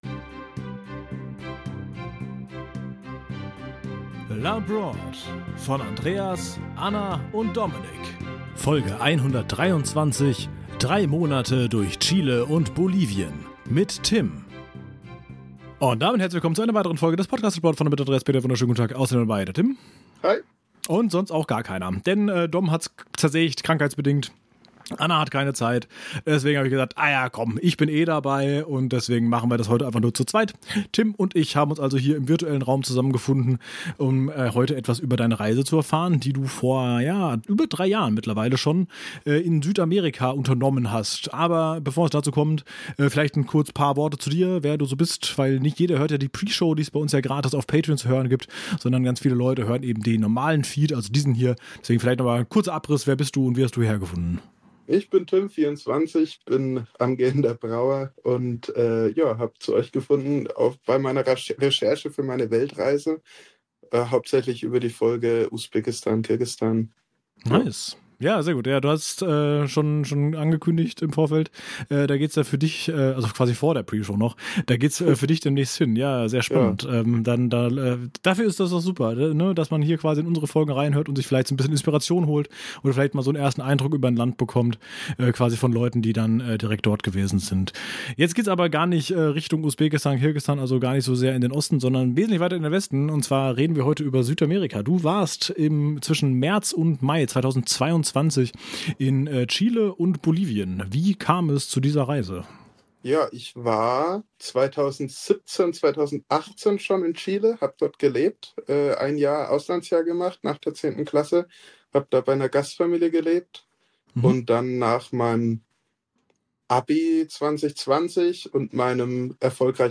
Gespräche